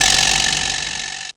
cch_perc_mid_rattle_eighty.wav